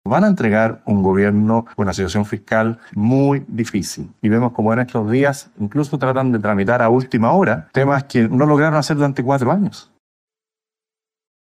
El presidente electo, José Antonio Kast, en medio del Foro Prioridades para Chile, organizado por ICARE, elevó el tono contra el actual Gobierno, cortando con el guante blanco que había primado tras las elecciones.